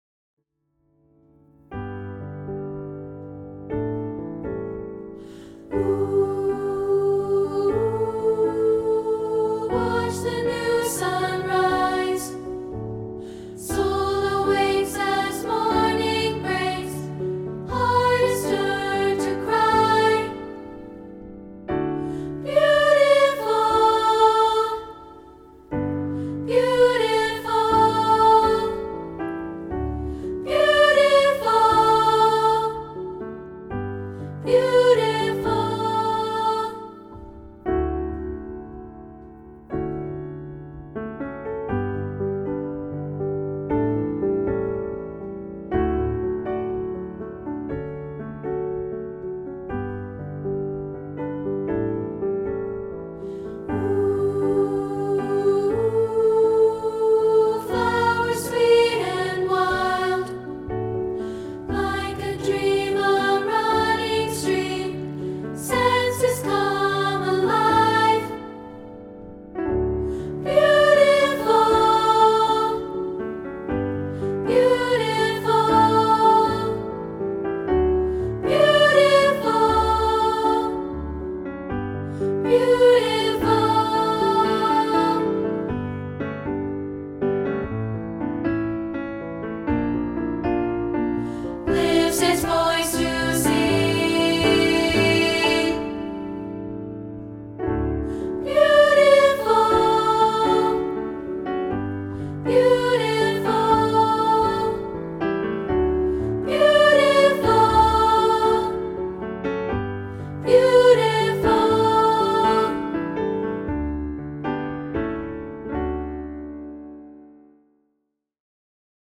including this rehearsal track of part 2, isolated.